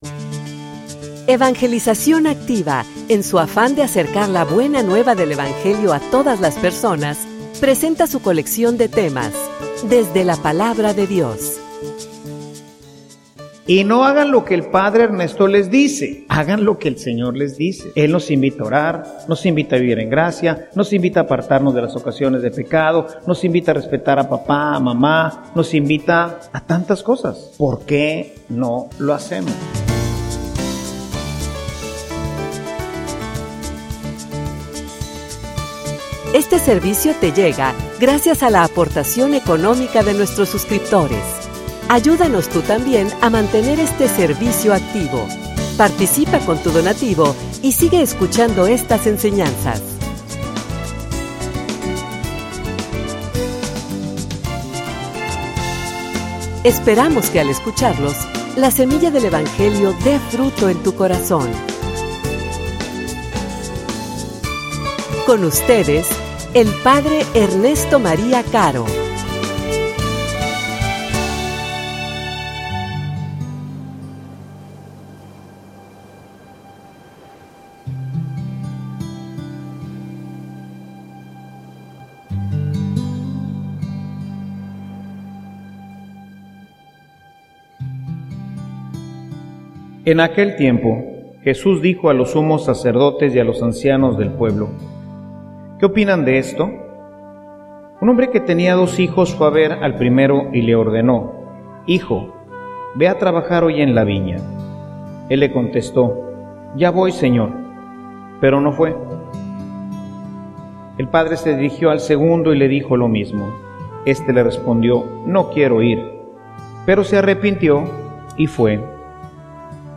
homilia_Tu_te_vas_o_te_quedas.mp3